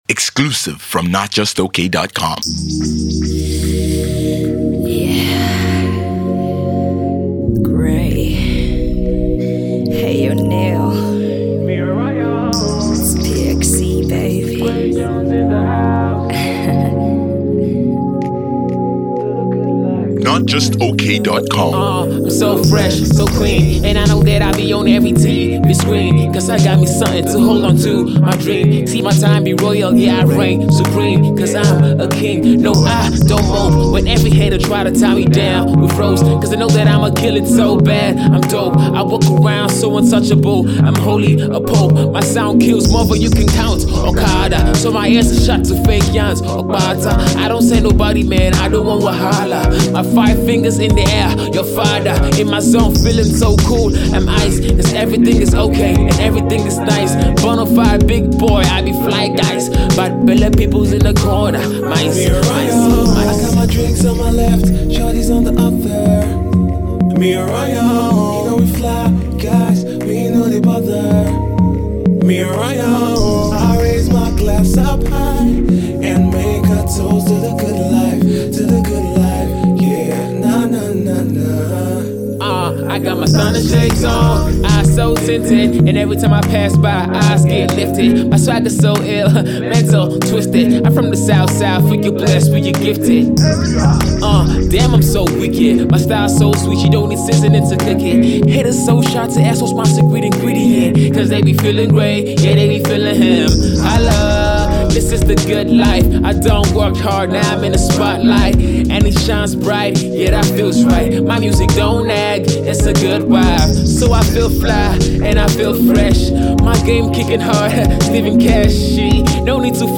sweet, simple and classy